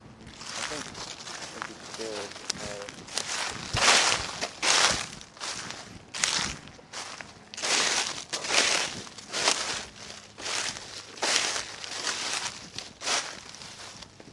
描述：以meduim速度碾碎一片叶子。
Tag: 叶紧缩 WAV 秋天 叶子 死叶